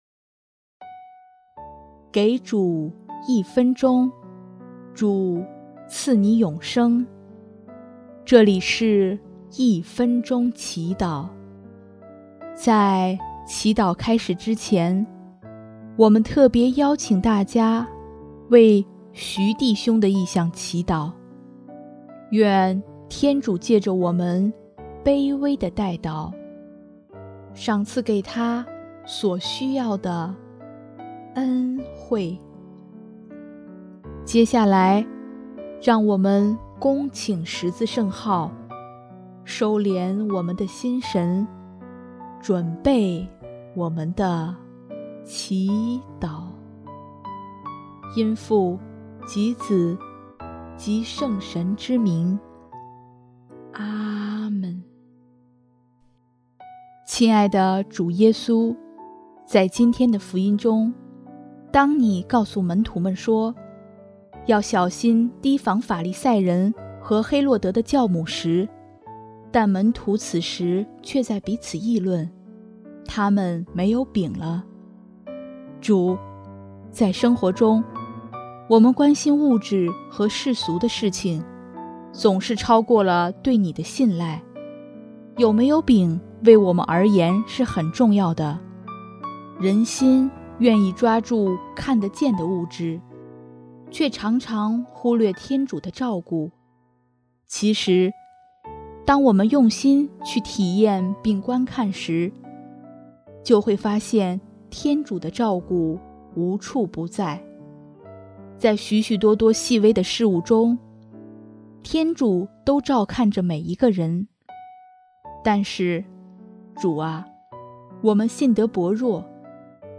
音乐： 第一届华语圣歌大赛参赛歌曲《主，我相信》